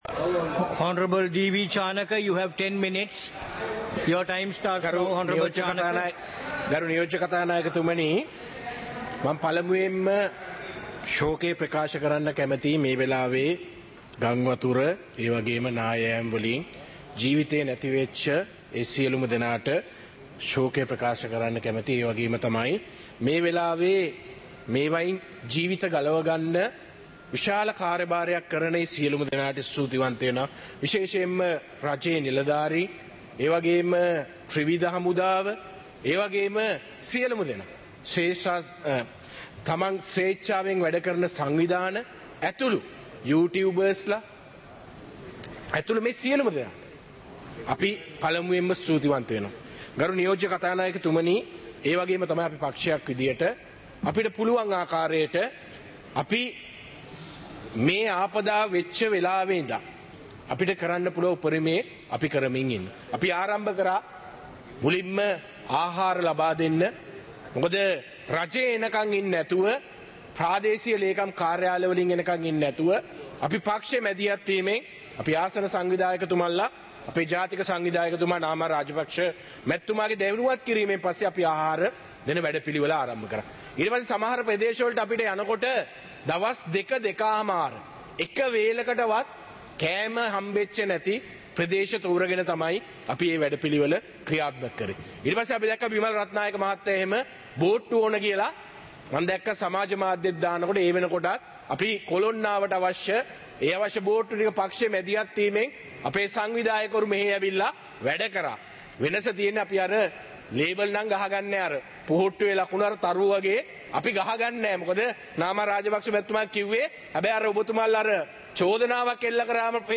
இலங்கை பாராளுமன்றம் - சபை நடவடிக்கைமுறை (2025-12-05)
பாராளுமன்ற நடப்பு - பதிவுருத்தப்பட்ட